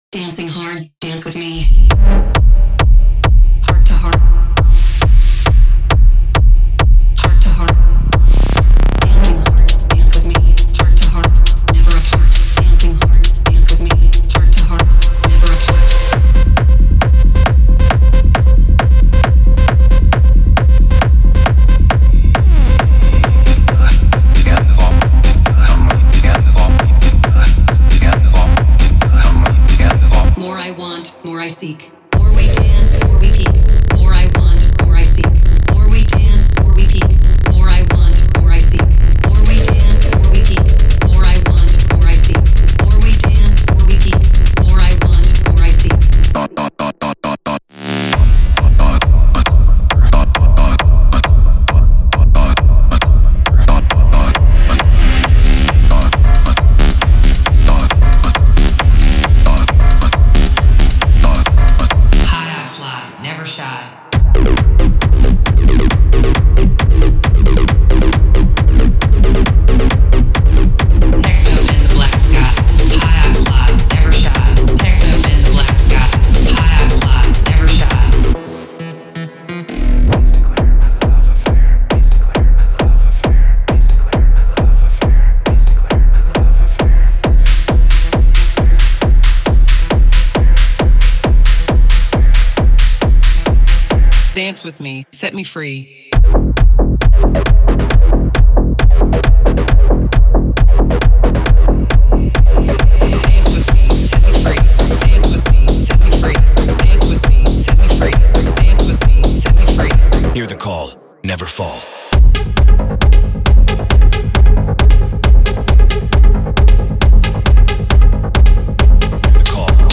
Genre:Techno
このパックの中心は、頭を叩きつけるドラムループと、AIが生成した催眠術のようなボーカルフックにあります。
ご注意：このオーディオ・デモは、ラウドで圧縮された均一なサウンドに加工されています。
デモサウンドはコチラ↓